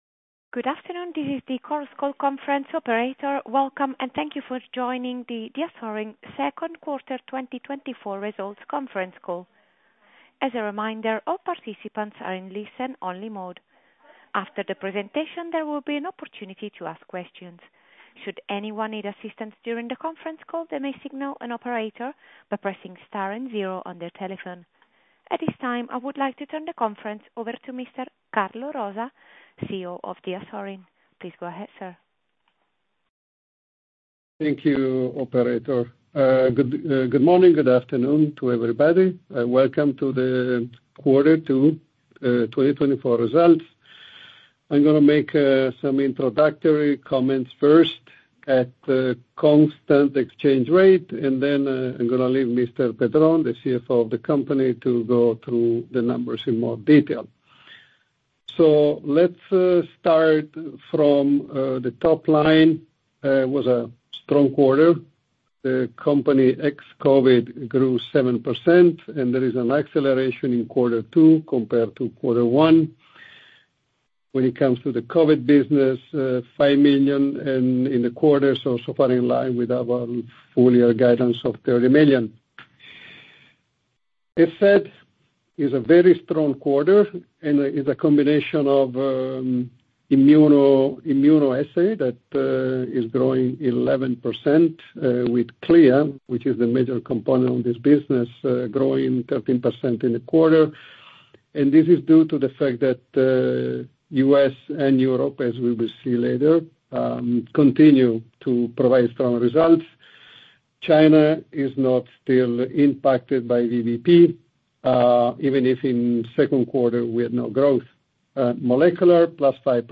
Diasorin H1 2024 Conference Call.mp3